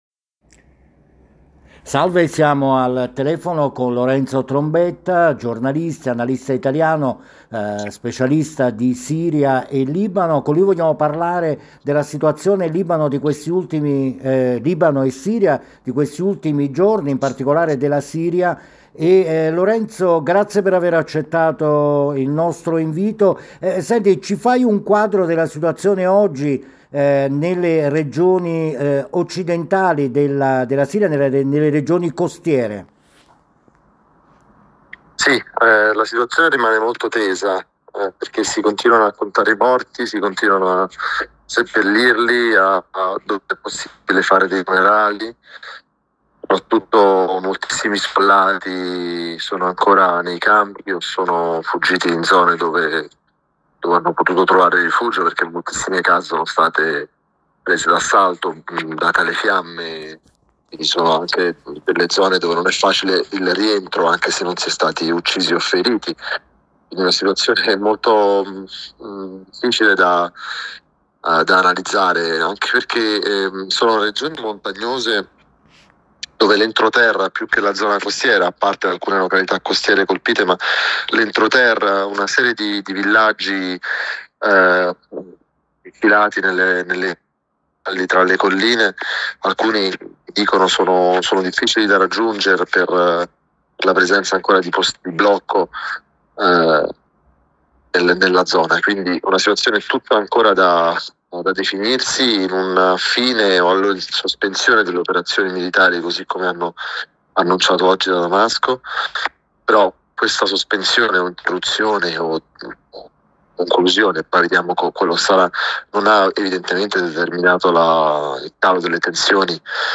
La Siria sta vivendo giorni di sangue e scontri. Chi diceva di sperare in una svolta verso la democrazia dopo la caduta di Assad, oggi guarda con sgomento alle stragi di alawiti compiute dalle forze di sicurezza agli ordini di Ahmed Sharaa. Intervista